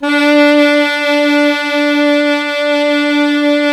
SAX 2 ALTO01.wav